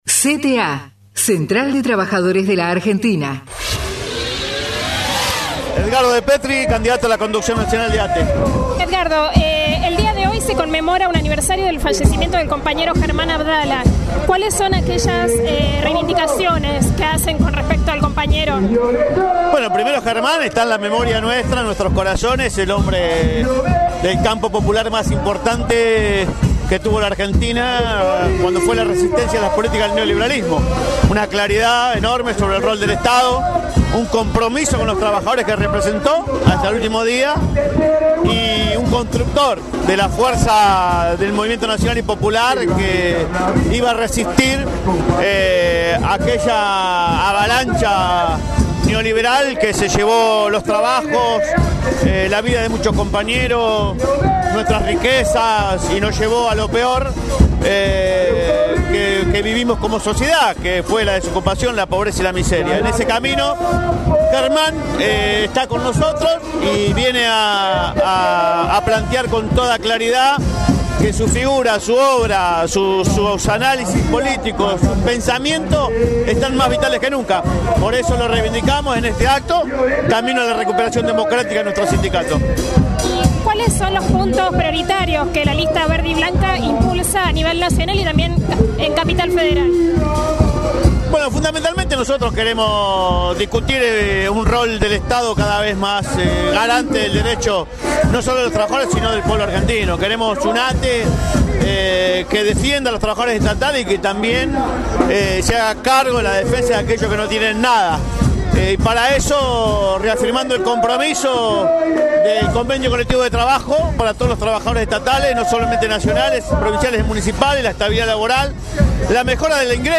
TESTIMONIOS // ACTO ATE LISTA VERDE-BLANCA – Central de Trabajadores y Trabajadoras de la Argentina